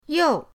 you4.mp3